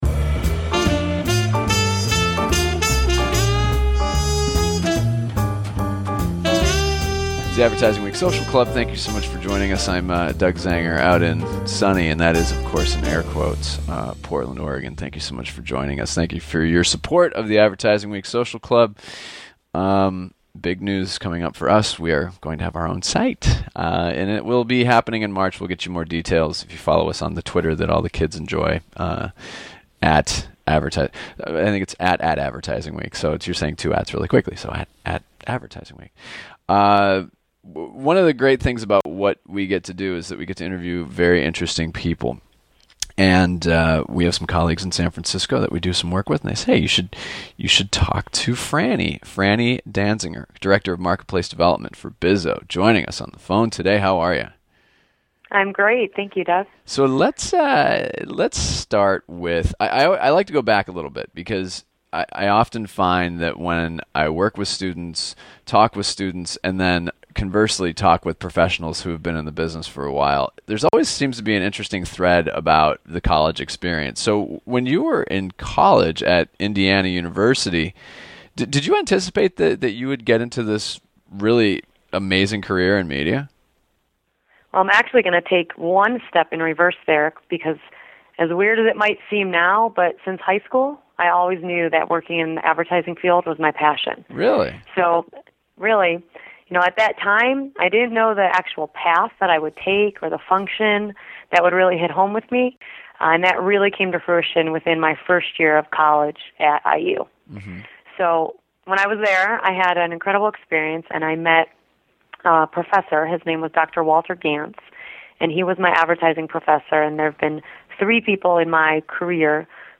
AWSC Conversation